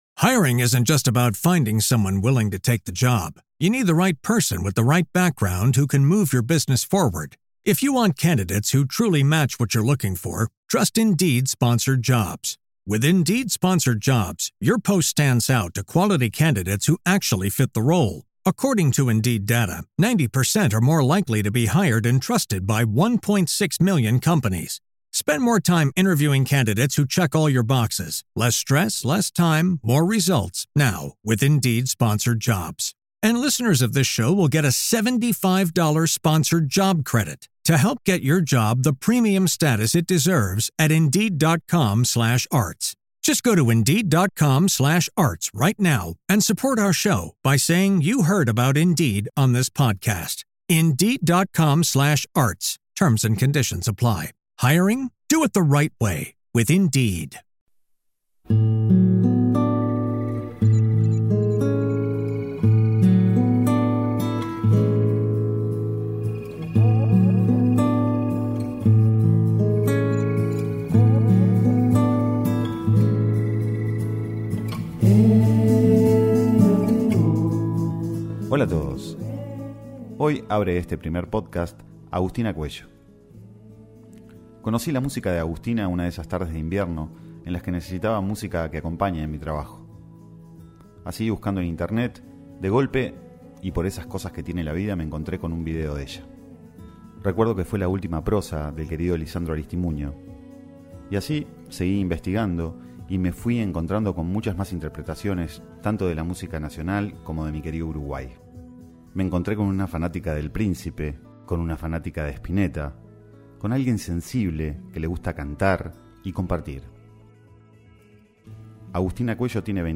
En este año comenzó a incursionar en la composición y a conformar su proyecto como solista, interpretando algunas canciones de su autoría, junto con un repertorio de covers de diversos artistas.